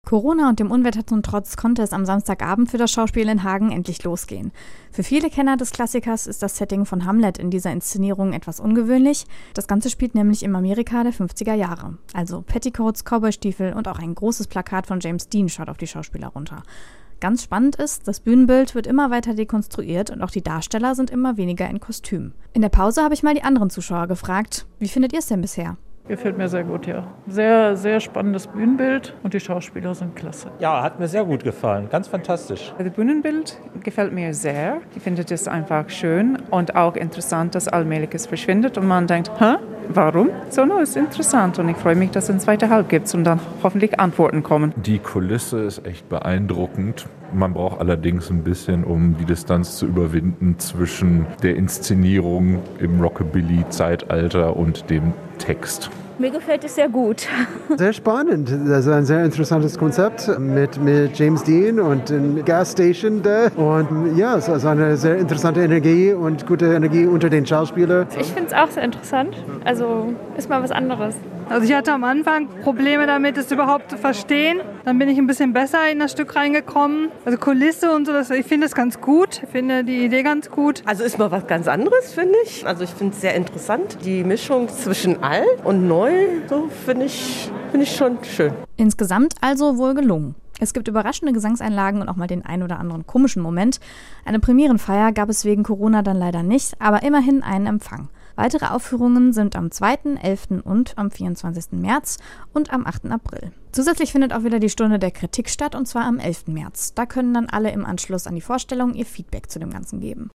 Wir waren natürlich mit dabei und haben auch mal die anderen Zuschauer nach ihrer Meinung gefragt.